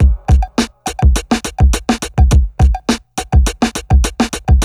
• 103 Bpm HQ Breakbeat Sample C Key.wav
Free breakbeat sample - kick tuned to the C note. Loudest frequency: 839Hz
103-bpm-hq-breakbeat-sample-c-key-rf7.wav